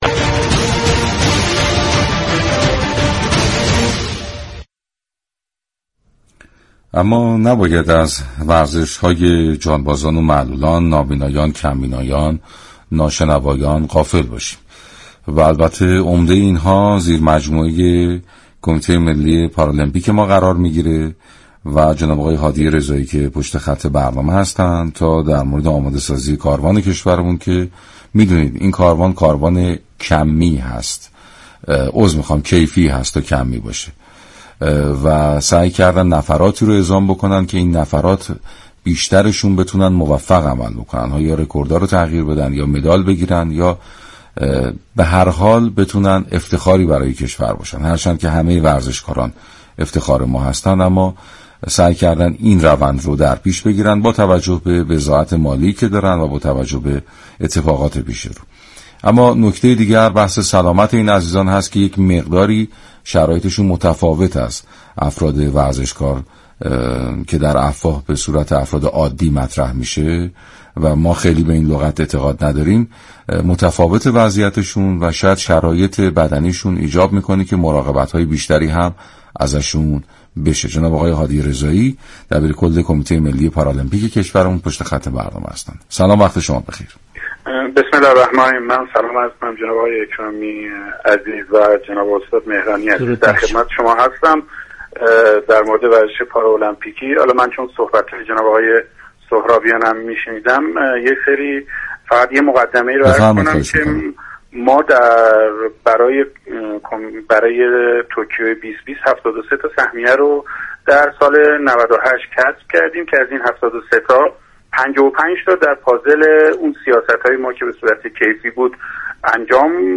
این برنامه با رویكرد بحث و بررسی درباره موضوعات روز ورزش كشور همه روزه به جز جمعه ها ساعت 18:30 به مدت 90 دقیقه از شبكه رادیویی ورزش تقدیم علاقمندان می شود.